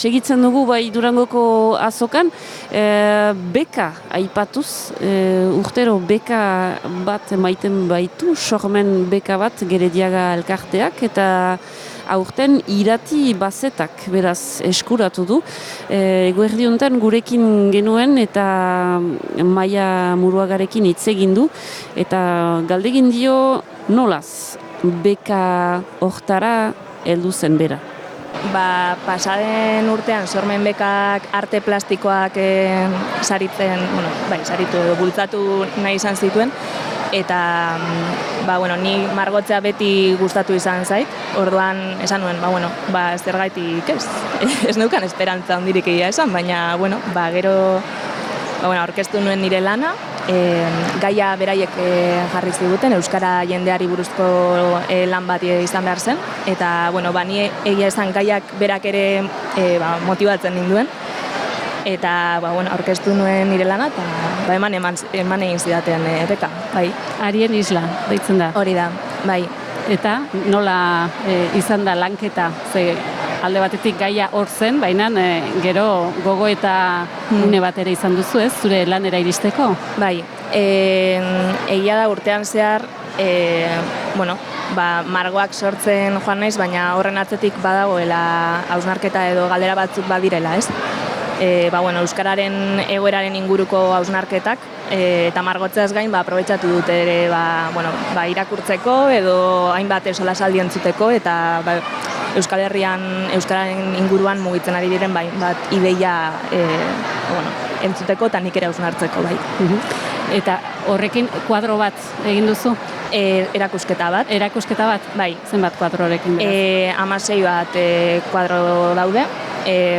Gaurkoan Durangoko Azokatik Zebrabidea saio berezia izan dugu.